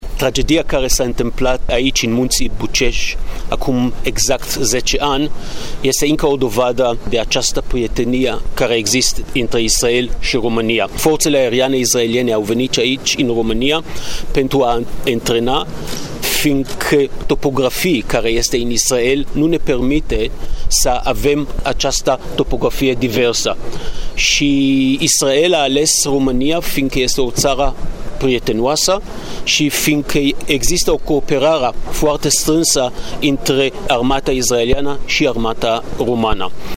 La monumentul ridicat între satul Simon și locul din Bucegi în care a căzut elicopterul, a avut loc o ceremonie de cinstire a celor 7 militari.
Ambasadorul Israelului la Bucuresti, Excelenta Sa, domnul David Saranga: